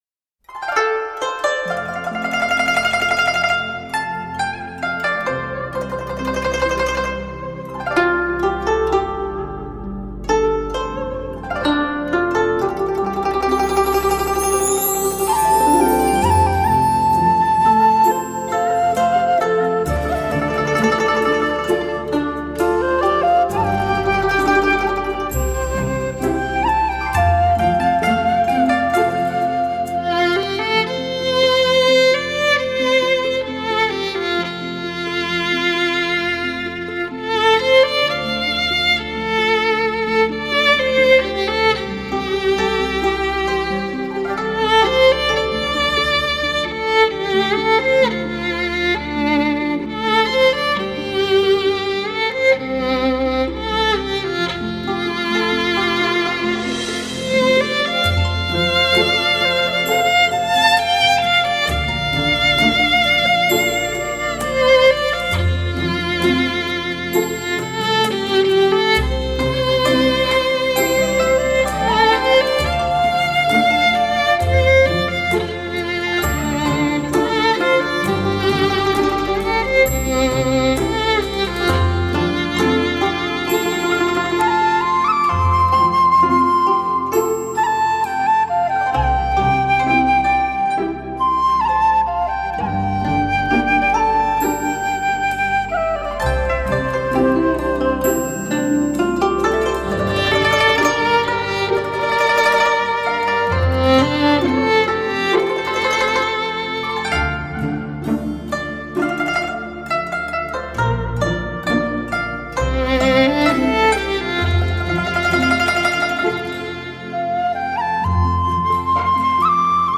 小提琴演奏
沁人心扉的娴熟弓法